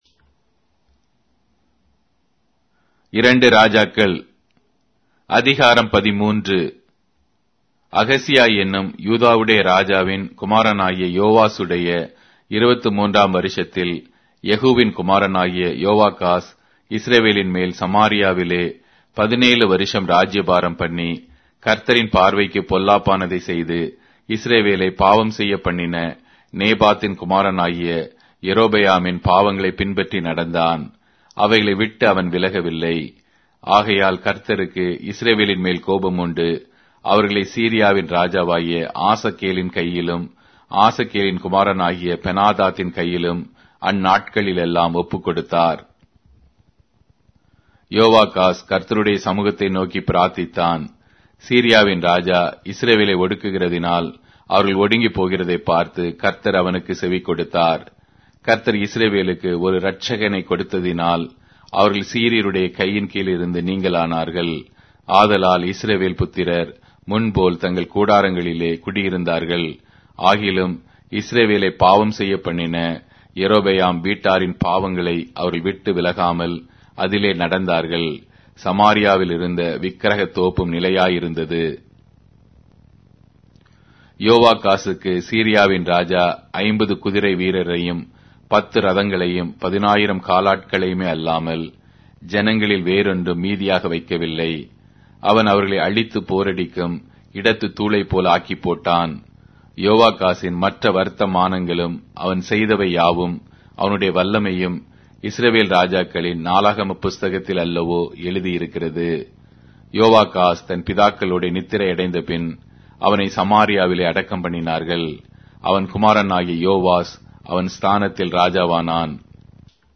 Tamil Audio Bible - 2-Kings 25 in Irvpa bible version